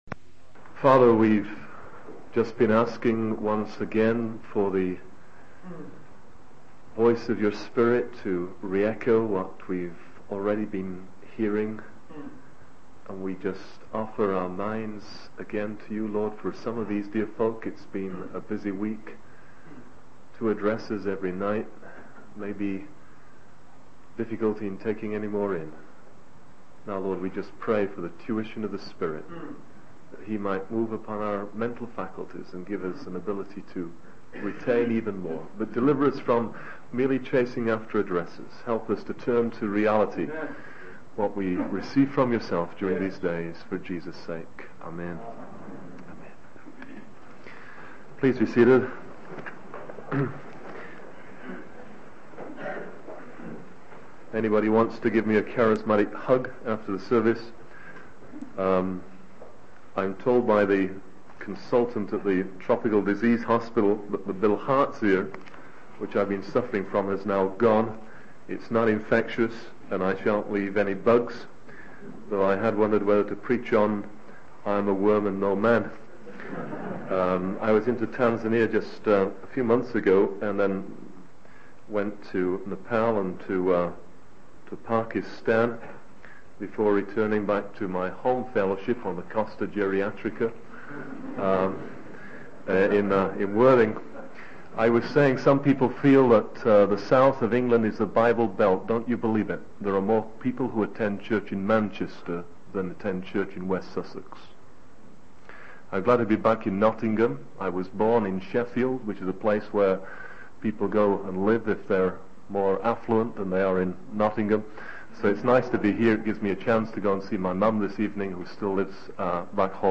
The sermon concludes with a call to avoid the fate of Lot, who, though saved, has little to show for his life in Christ.